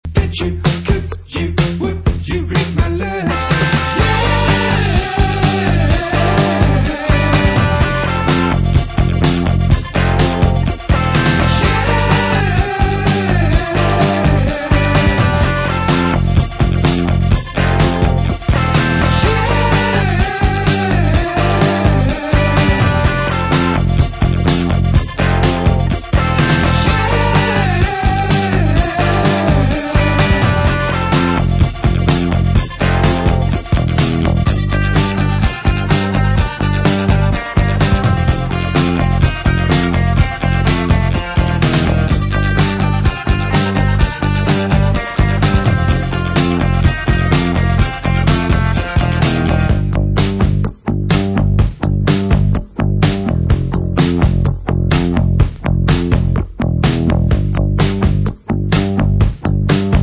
They play DISCO.